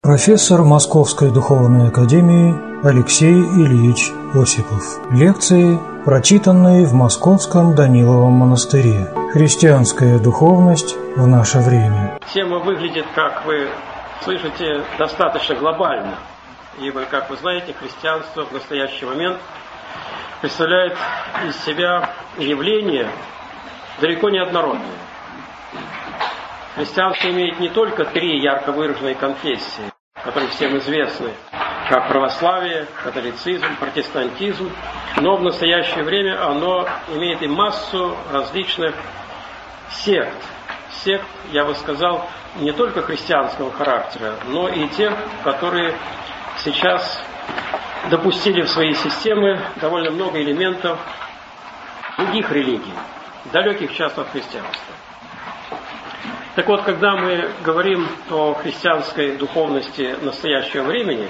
Аудиокнига Христианская духовность в наше время | Библиотека аудиокниг
Aудиокнига Христианская духовность в наше время Автор Алексей Осипов Читает аудиокнигу Алексей Осипов.